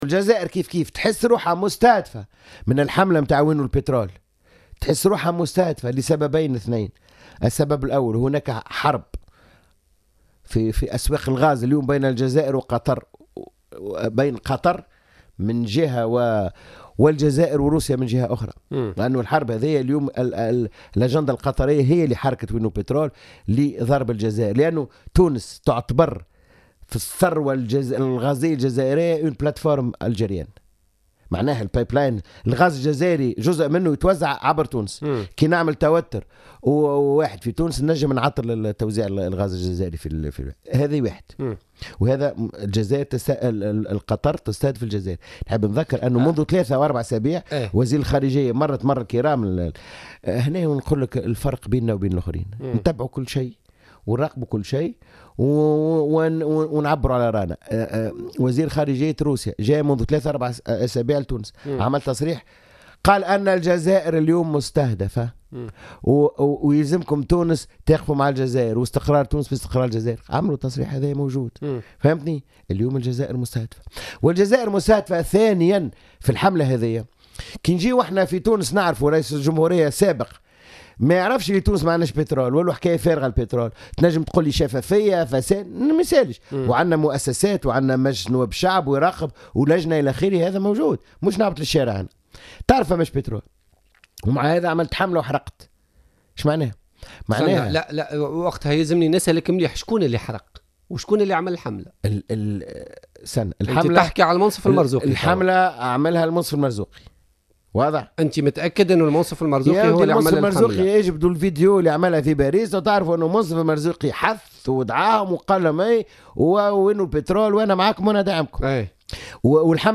قال سمير الطيب، أمين عام حزب المسار الديمقراطي الاجتماعي خلال برنامج "بوليتيكا" إن حملة وينو البترول انطلقت بعد عودة المنصف المرزوقي من قطر.